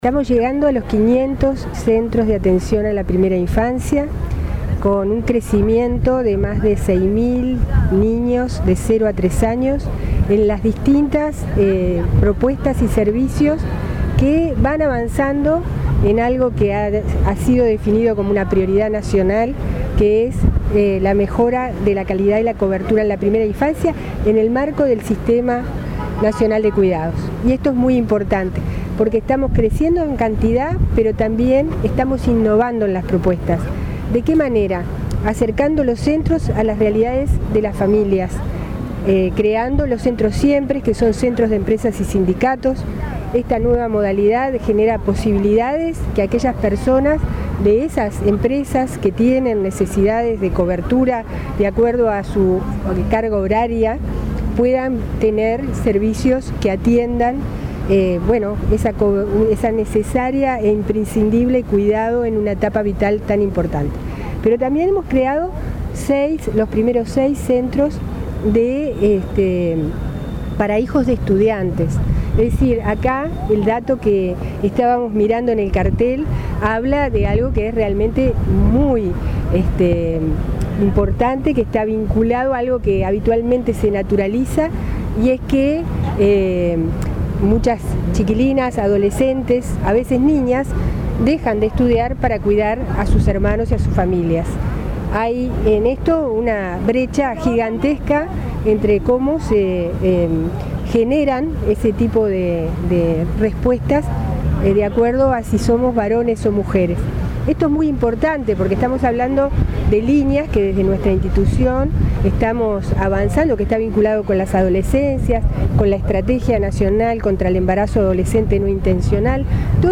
“Estamos llegando a los 500 centros de atención a la primera infancia, con una cobertura de 6.000 niños de entre 0 y 3 años en las distintas propuestas y servicios”, destacó la presidenta del INAU, Marisa Lindner, en el marco del lanzamiento del Mes de los Cuidados. Agregó que los centros son innovadores porque se adaptan a las realidades de las familias. Recordó que se han formado más de 3.500 educadores en primera infancia.